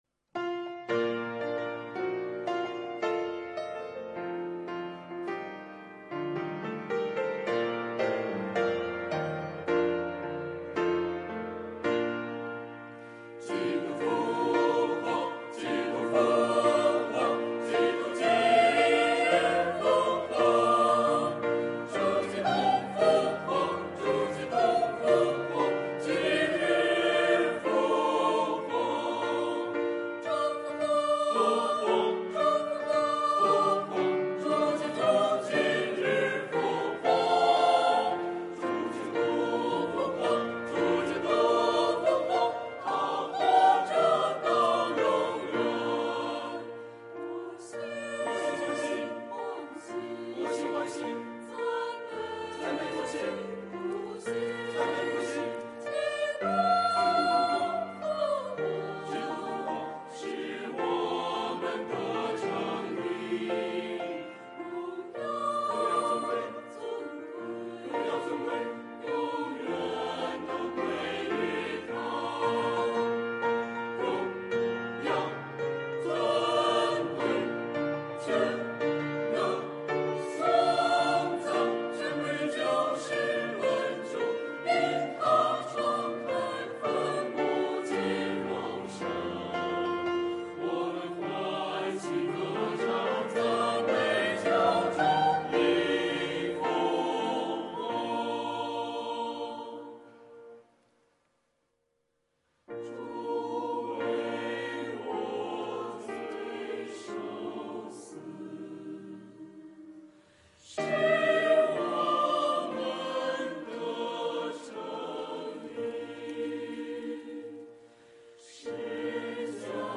团契名称: 青年、迦密诗班 新闻分类: 诗班献诗 音频: 下载证道音频 (如果无法下载请右键点击链接选择"另存为") 视频: 下载此视频 (如果无法下载请右键点击链接选择"另存为")